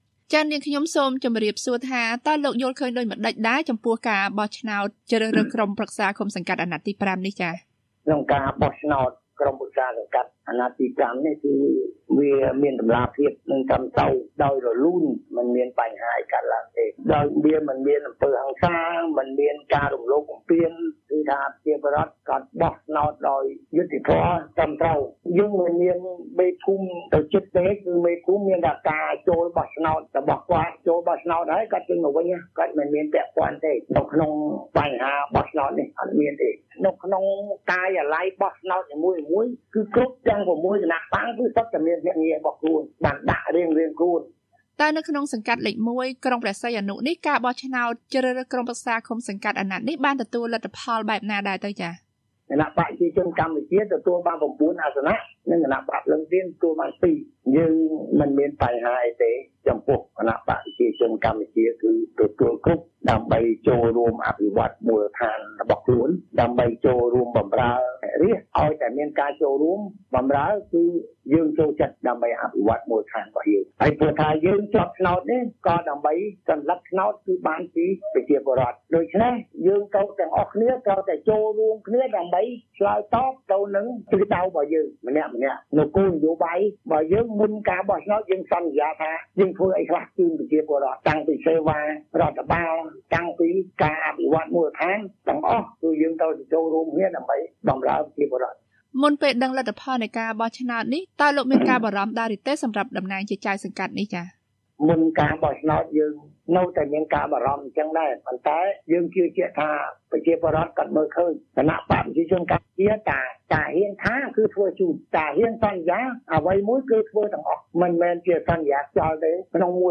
បានសម្ភាសន៍លោក ឈិត សូផាត ដែលបានជាប់ឆ្នោតបន្តជាចៅសង្កាត់លេខ ១ ក្រុងព្រះសីហនុ ខេត្តព្រះសីហនុ។